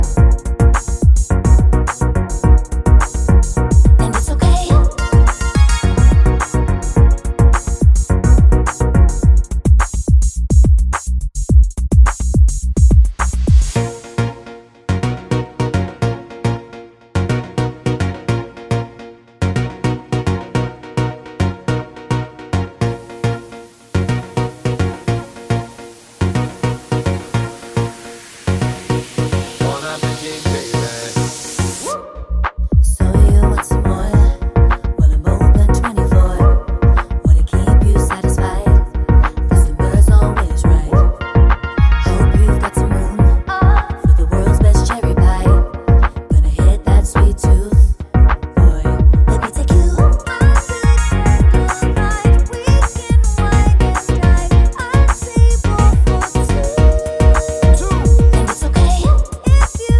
for duet Pop (2010s) 3:47 Buy £1.50